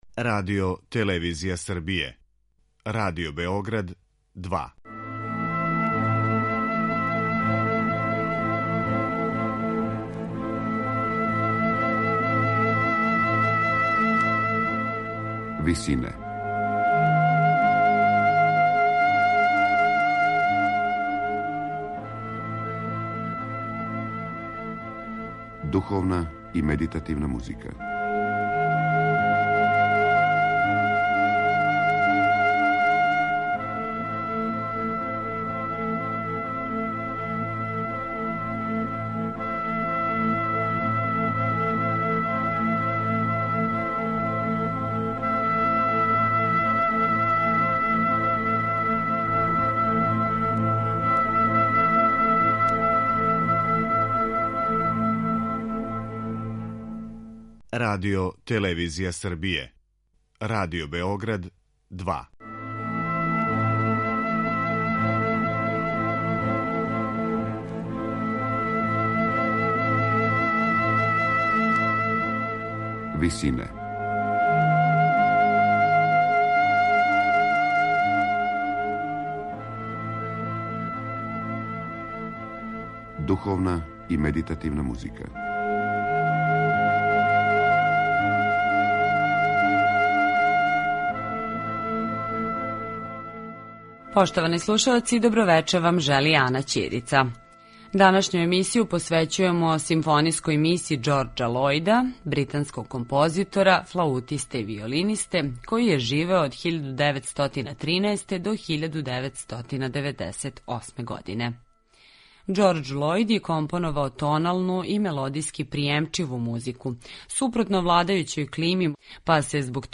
тоналну и мелодијски пријемчиву музику
суштински позноромантичарски стил
читавог спектра оркестарских боја